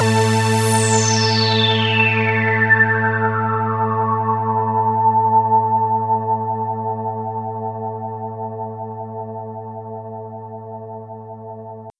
Synth 41.wav